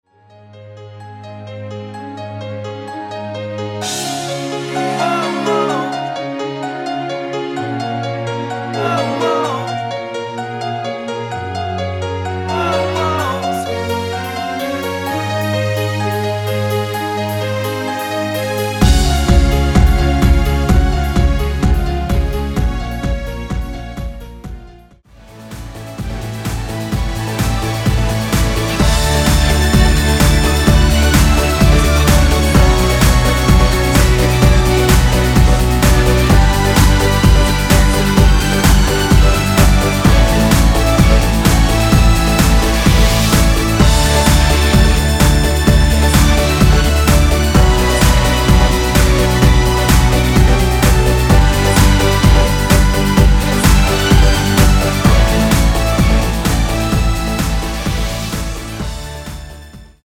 엔딩이 페이드 아웃이라 엔딩을 만들어 놓았습니다.(미리듣기 확인)
Db
앞부분30초, 뒷부분30초씩 편집해서 올려 드리고 있습니다.